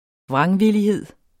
Udtale [ ˈvʁɑŋviliˌheðˀ ]